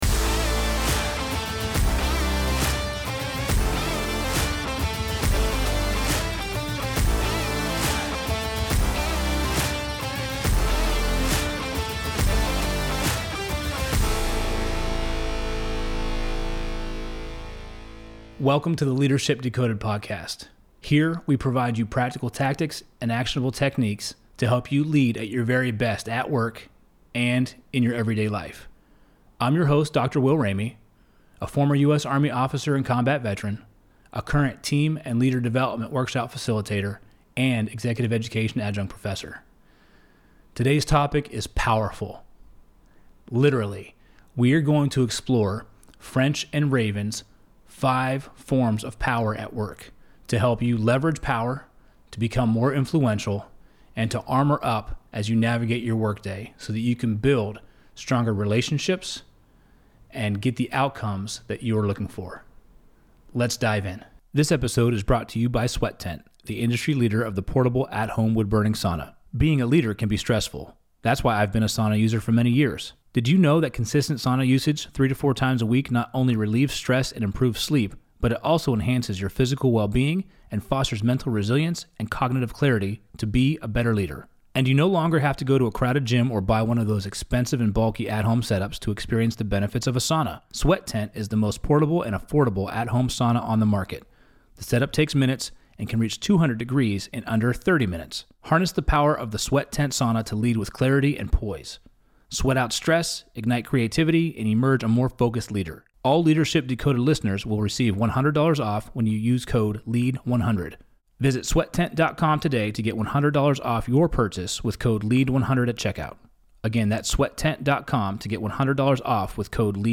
Welcome to Ep.037 of the Leadership Decoded Podcast in the Loop Internet studio